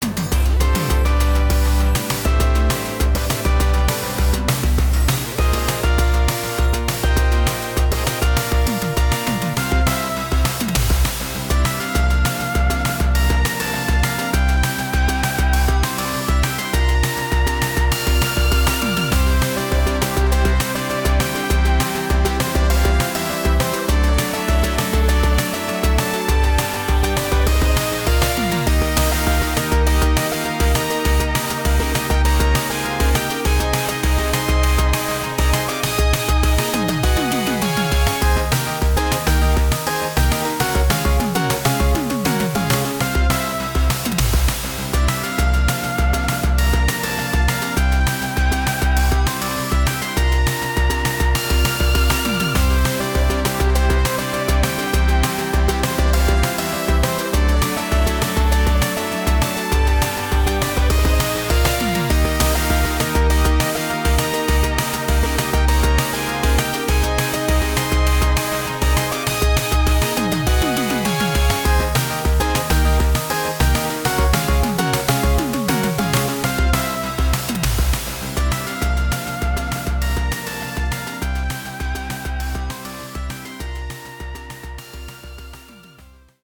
in chiave YM2151... doppio chip